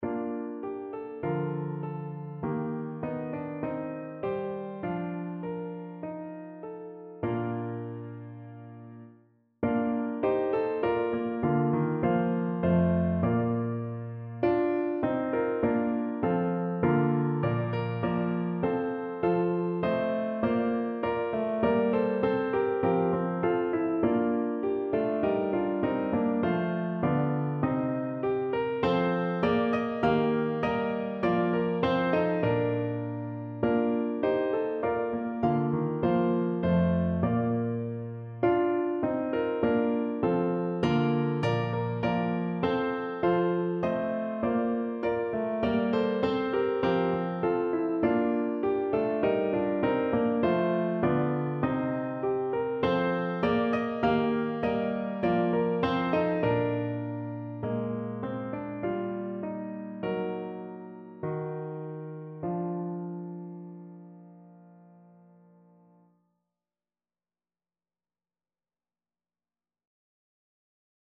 • SATB und Klavier [MP3] 1 MB Download